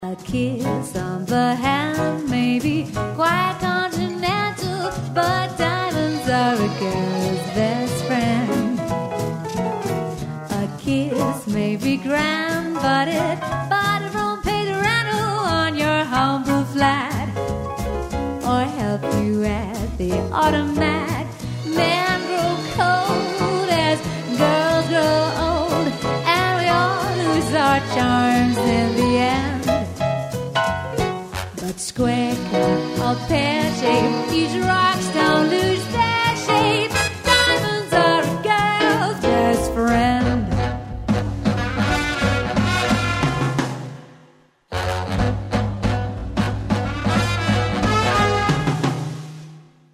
• Solo Singer